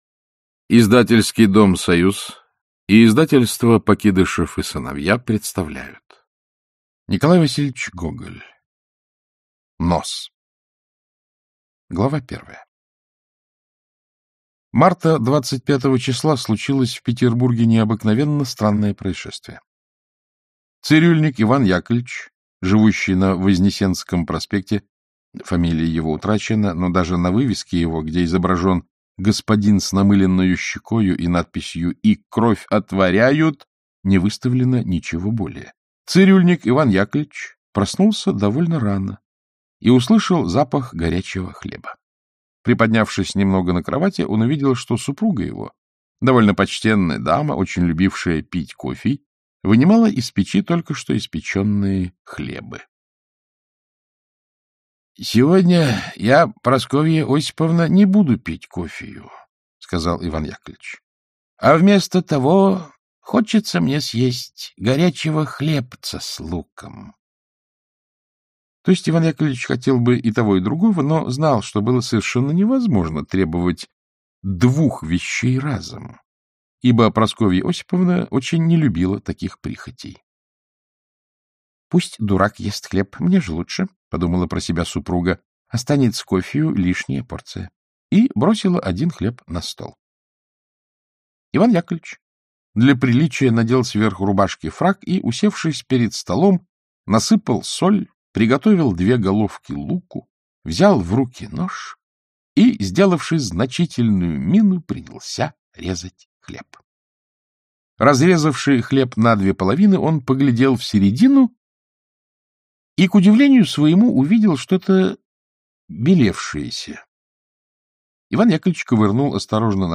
Аудиокнига Нос | Библиотека аудиокниг
Aудиокнига Нос Автор Николай Гоголь Читает аудиокнигу Александр Клюквин.